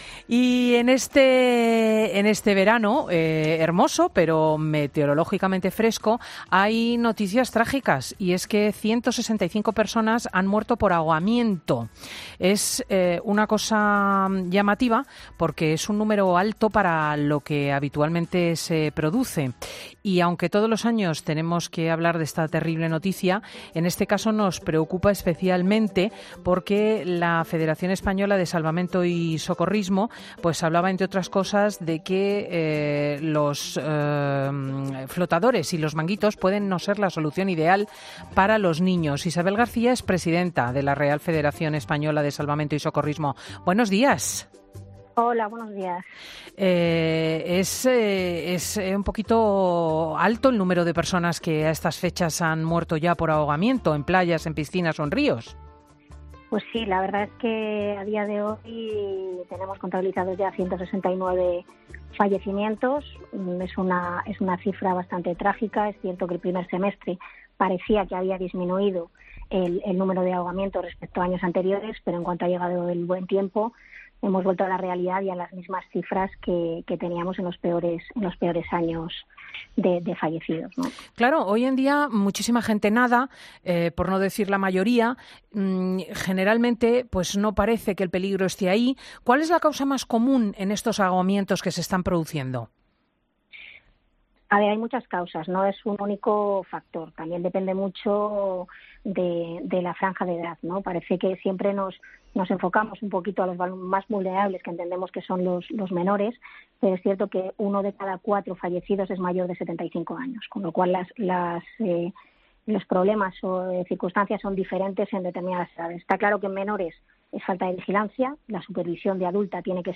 Escucha la entrevista completa en el programa 'Fin de Semana' con Cristina López Schlichting para saber más sobre los sistemas de flotación más seguros para los niños y cómo actuar en caso de ahogamiento.